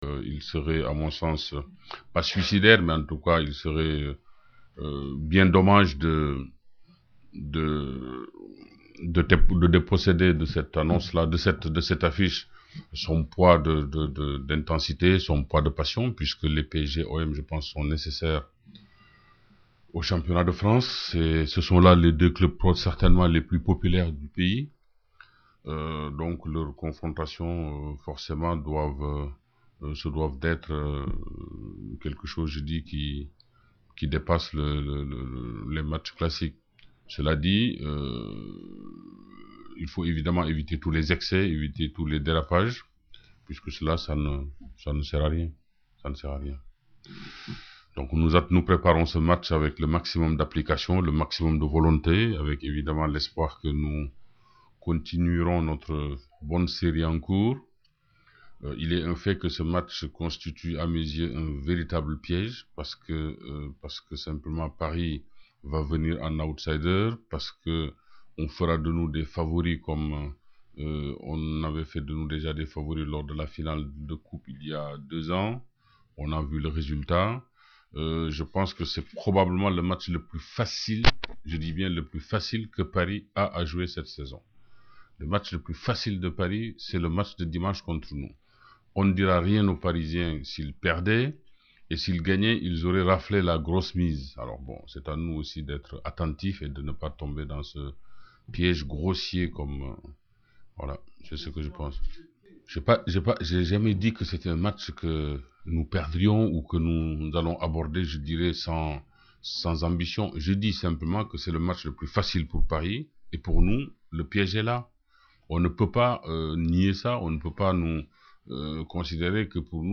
Pour ceux qui ne l’ont pas connu, parmi les plus jeunes fans de l’OM, ou les autres qui n’ont jamais entendu s’exprimer Pape Diouf dans son rôle de président de l’OM, voici quelques-unes de ses interventions parmi les plus savoureuses devant la presse à La Commanderie.